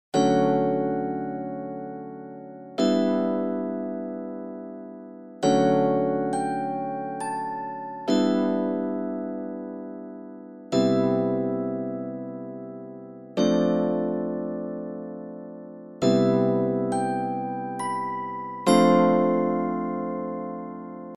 3 Octaves
Grade 3 - Middle School